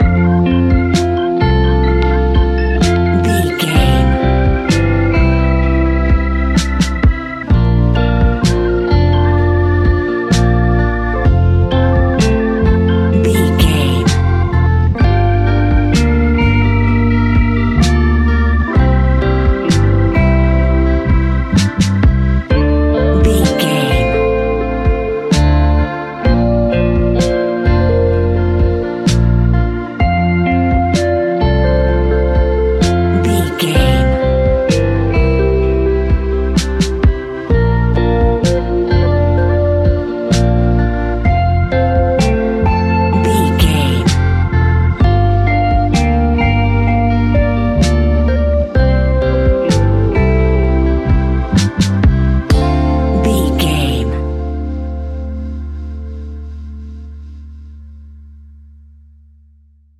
Ionian/Major
laid back
Lounge
sparse
chilled electronica
ambient
atmospheric